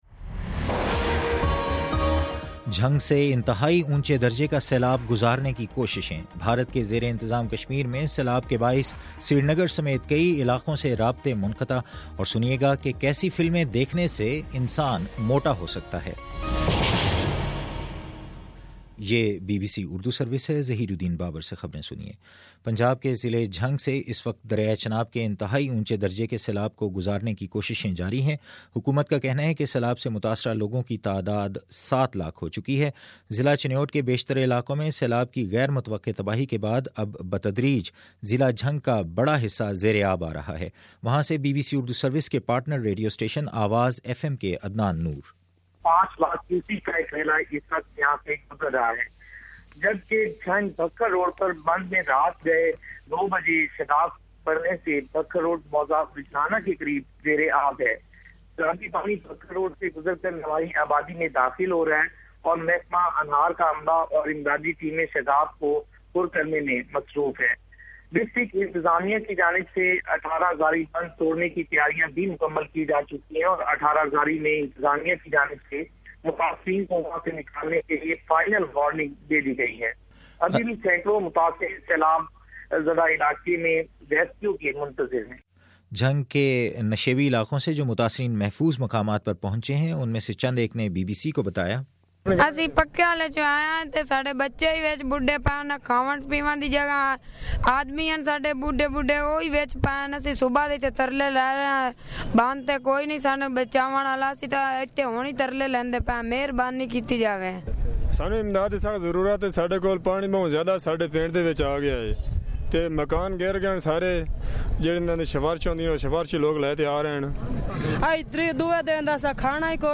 دس منٹ کا نیوز بُلیٹن روزانہ پاکستانی وقت کے مطابق صبح 9 بجے، شام 6 بجے اور پھر 7 بجے۔